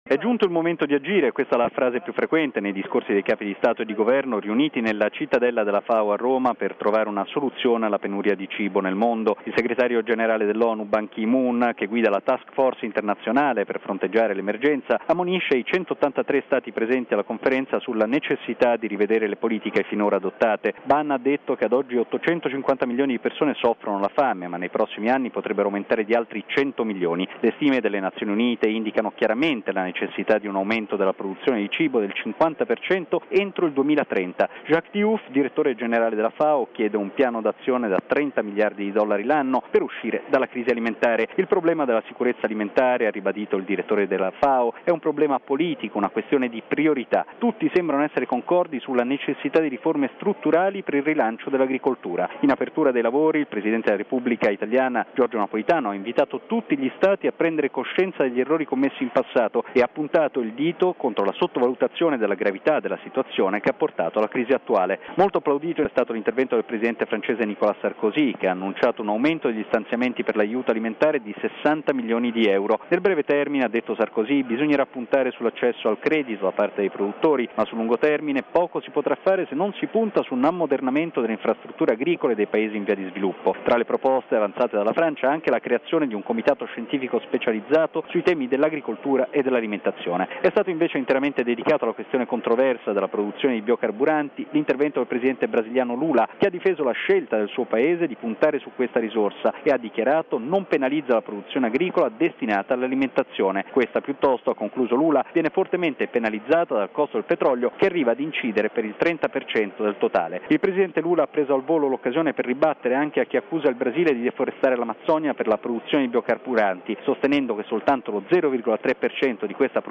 ◊   Il presidente Napolitano ha aperto questa mattina a Roma i lavori del vertice della FAO dedicati alla sicurezza alimentare, ricordando le responsabilità di tutti nell’eliminazione delle cause che hanno provocato la penuria di cibo.